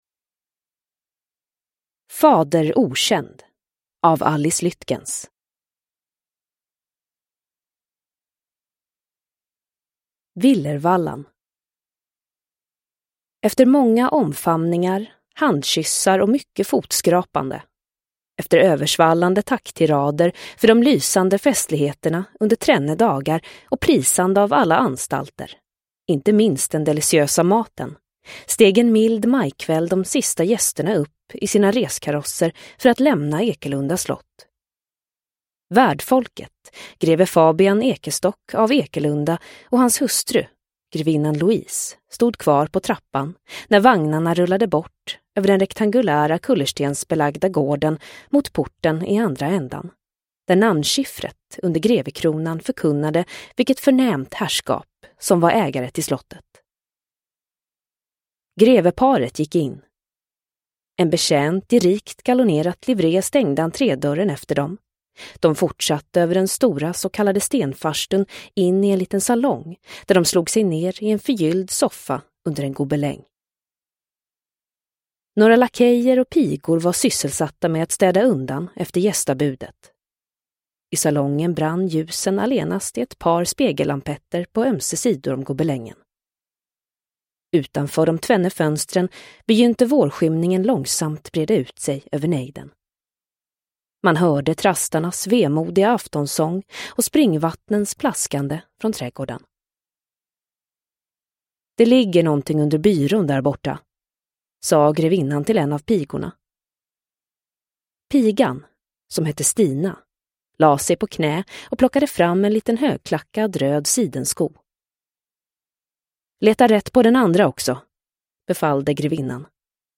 Fader: okänd – Ljudbok – Laddas ner